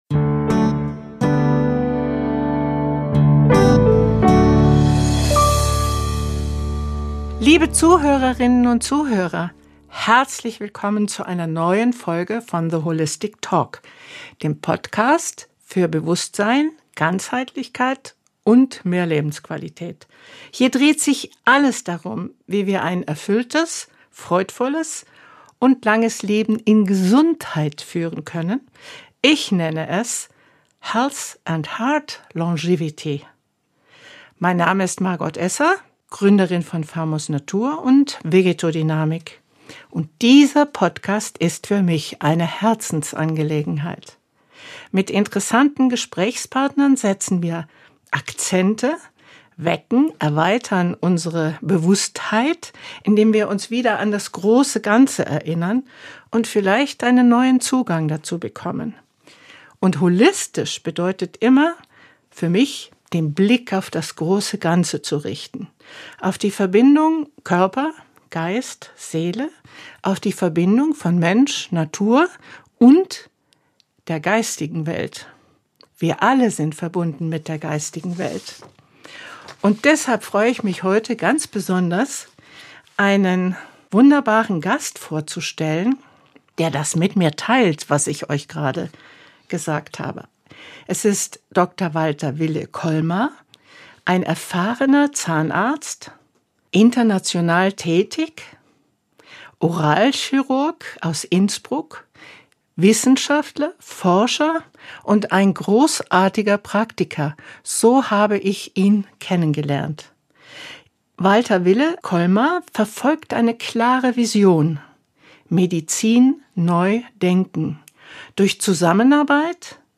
Ein Gespräch über stille Entzündungen, Biophotonen, Zellintelligenz und darüber, wie sich das große Ganze an einem oft übersehenen, aber zentralen Teil des Körpers zeigt: dem Mundraum und unseren Zähnen.